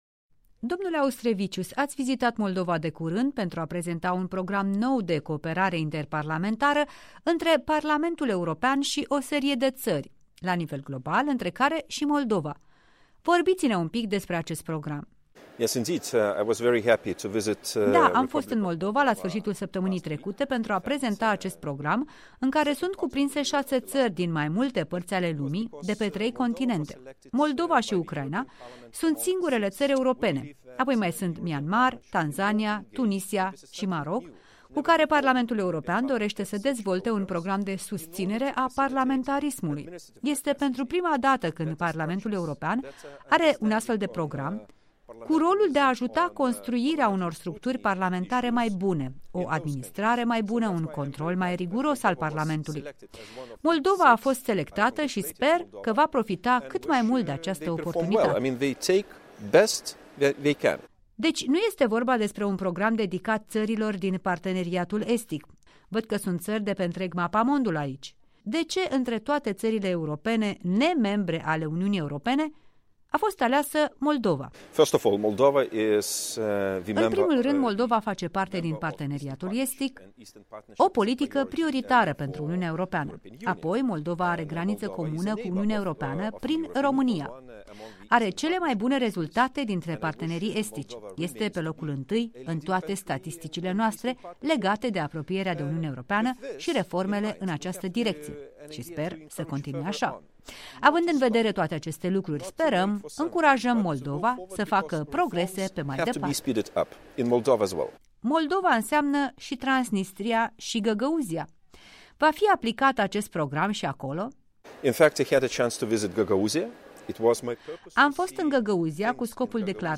Interviu cu raportorul Parlamentului European pentru R.Moldova